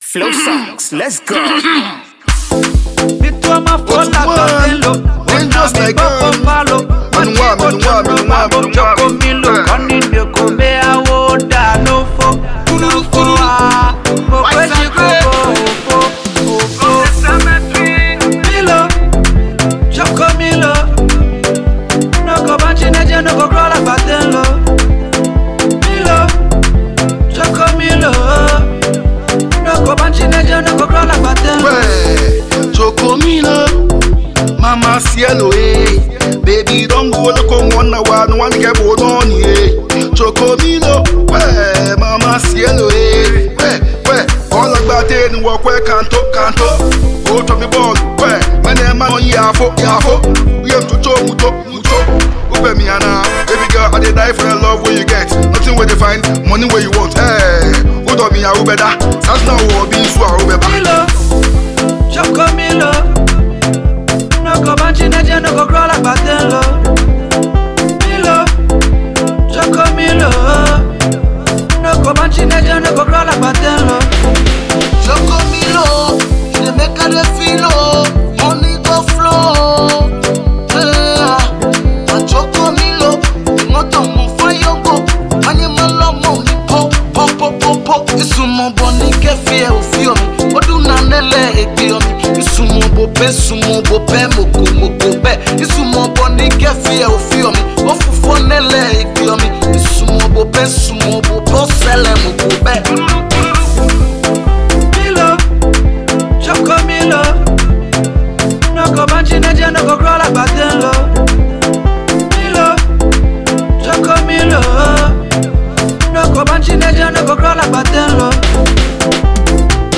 It is a catchy and lively melody.
Highlife and Afrobeat